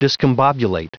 Prononciation du mot discombobulate en anglais (fichier audio)
Prononciation du mot : discombobulate